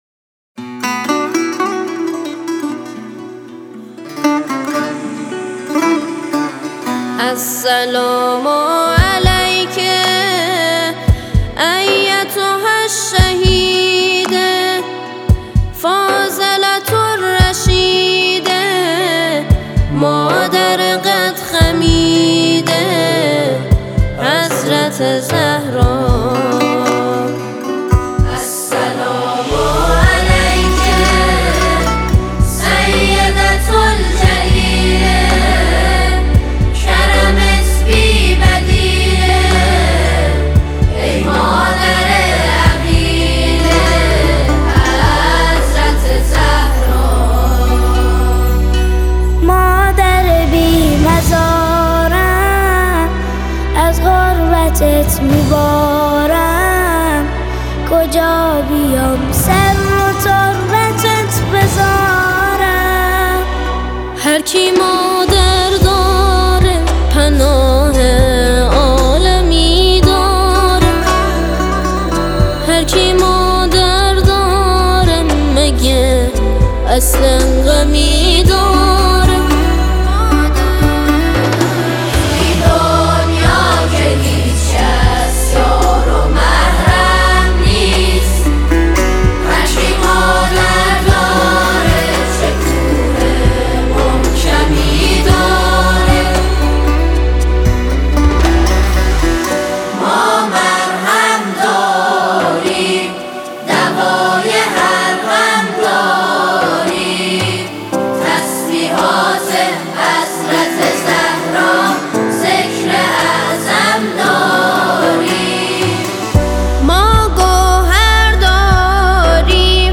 گروه سرود